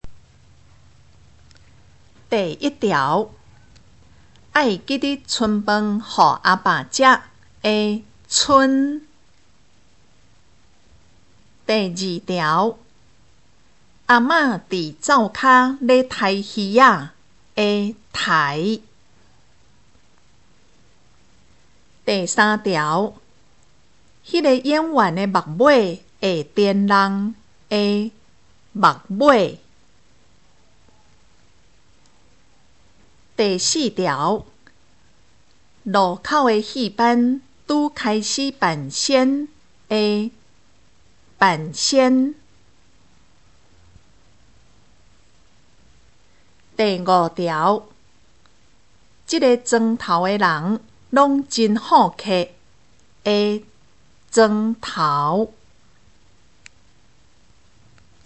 【國中閩南語2】每課評量(2)聽力測驗mp3